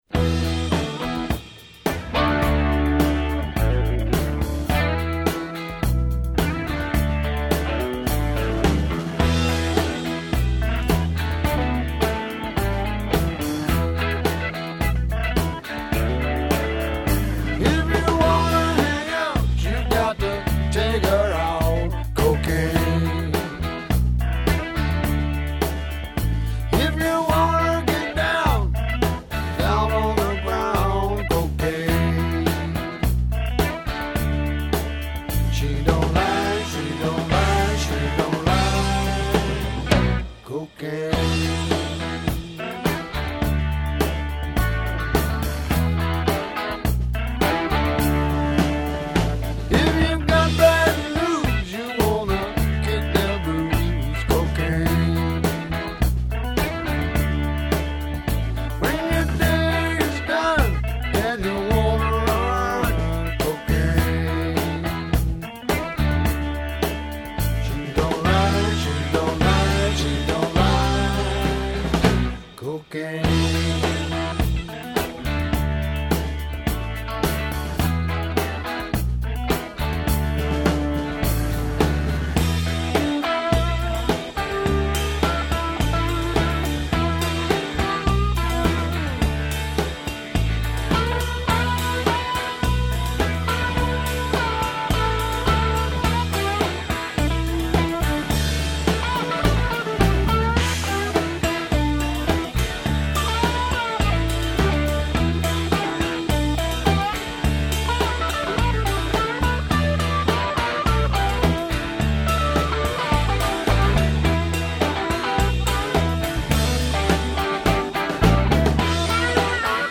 Blues Rock, Rock